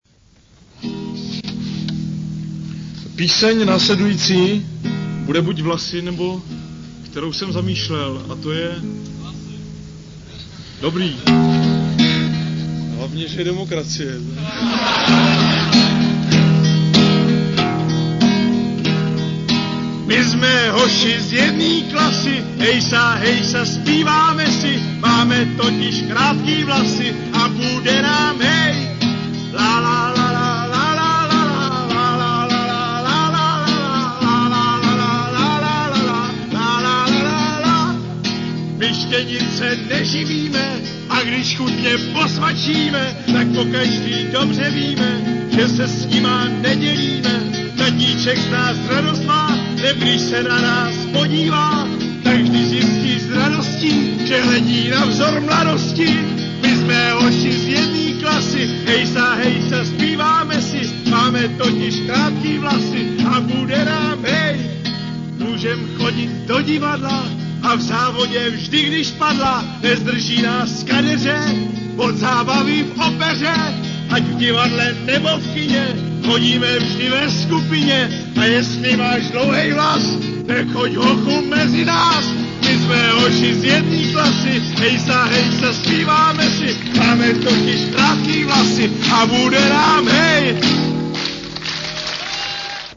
Je to znát na reakcích publika.